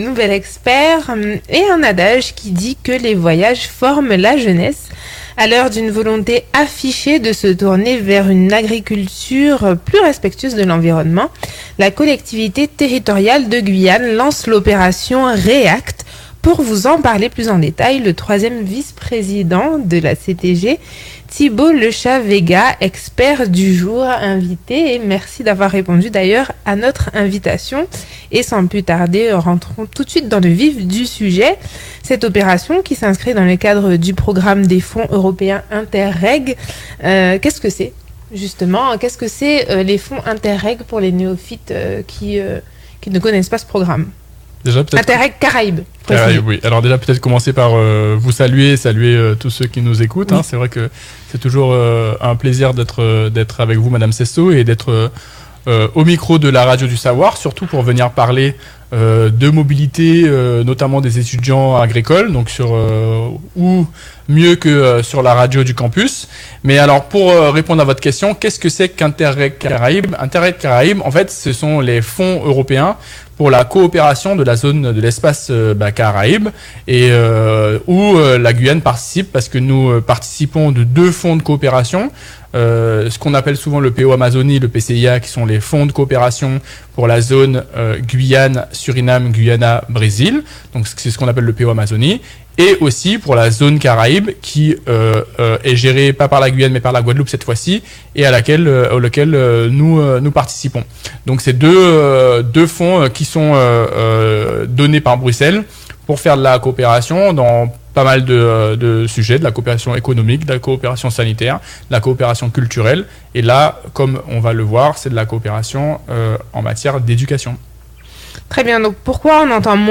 Thibault Lechat-Vega., 3ème vice-président à la CTG Collectivité Territoriale de Guyane était l'invité